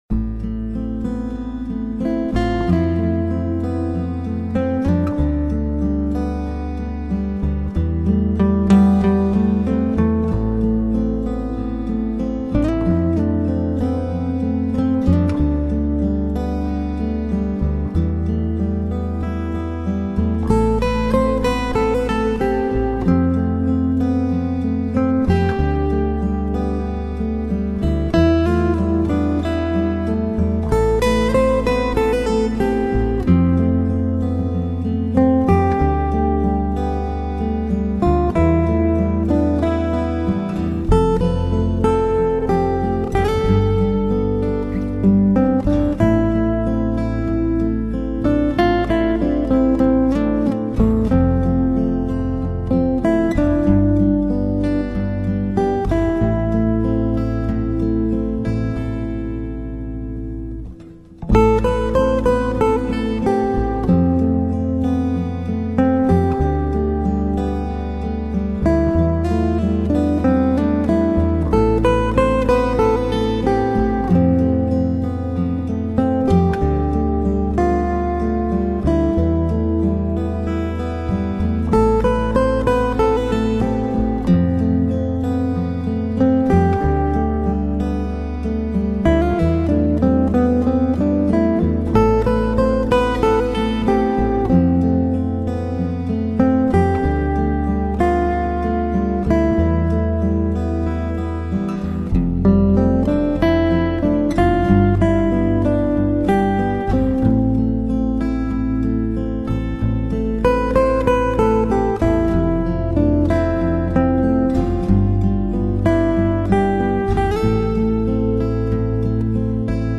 Всем завалинцам привет! У меня просьба.Подскажите пожалуйста кто знает название и исполнителя этого инструментала...